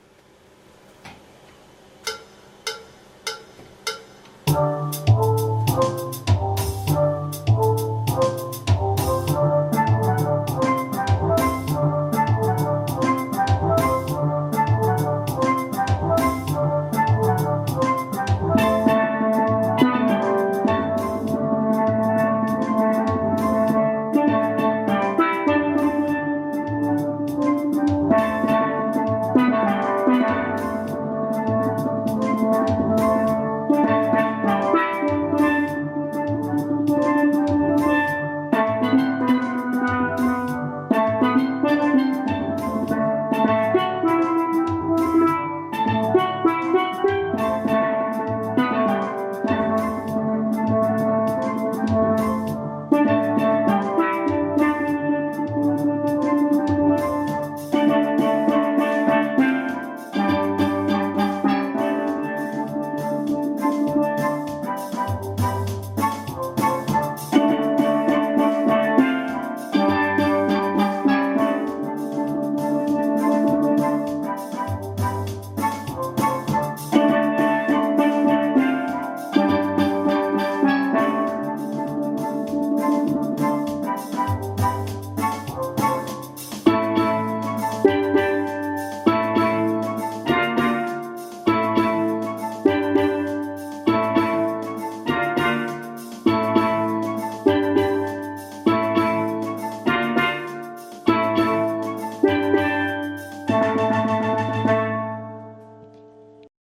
ALTO CALL OF THE WATER DANCER FILM .mp3